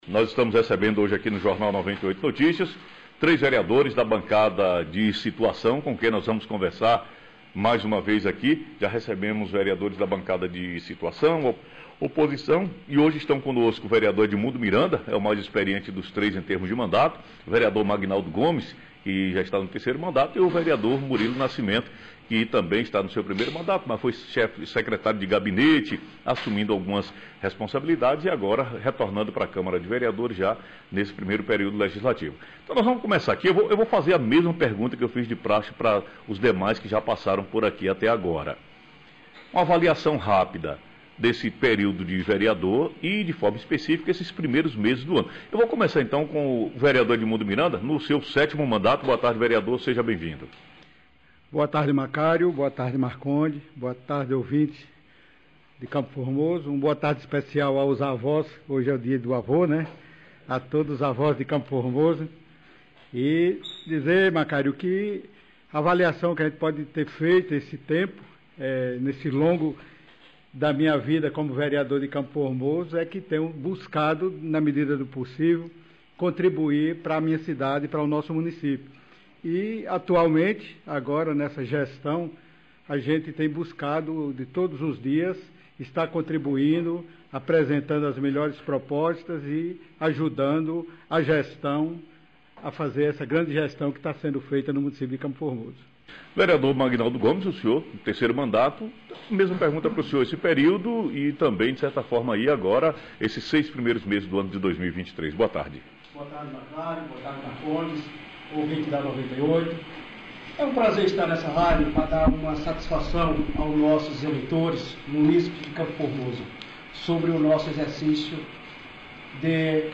Vereadores da situação do município de CFormoso, Magnaldo Gomes, Edimundo Miranda e Murilo Nascimento